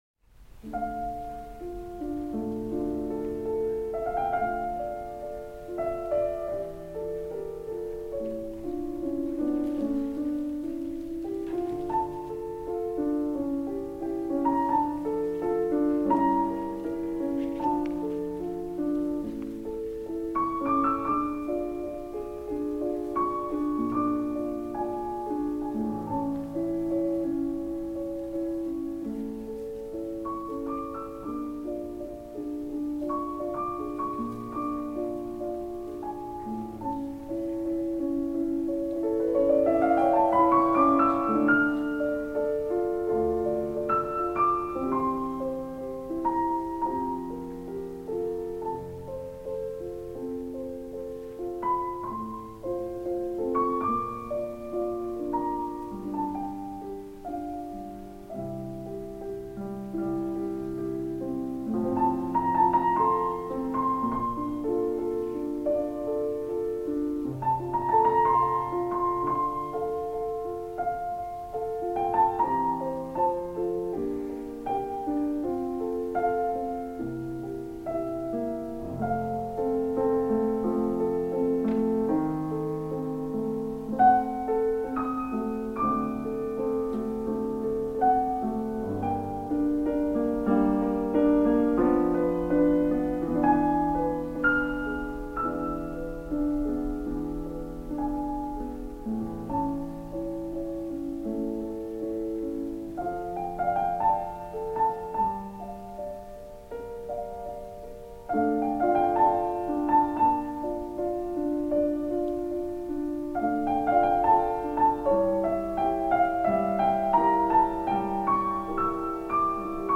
CD album